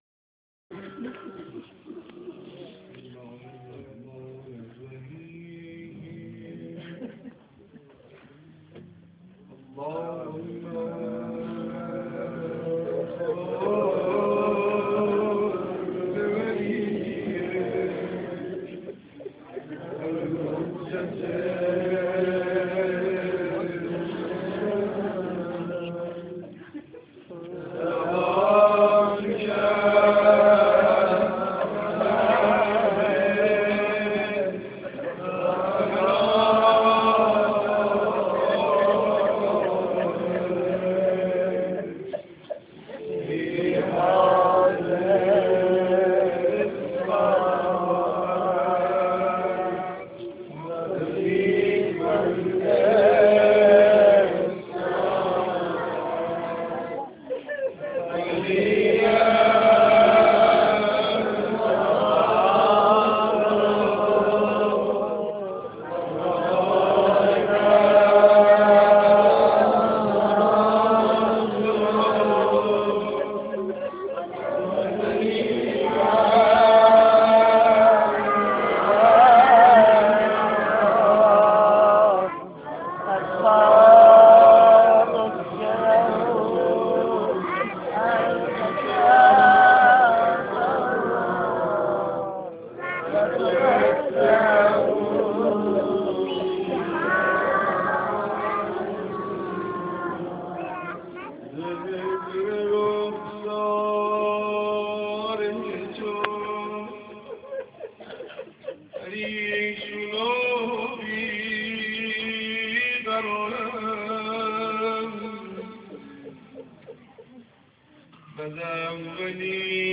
روضه-خوانی4.amr